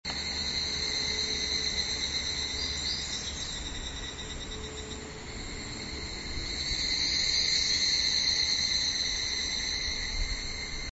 evening cicada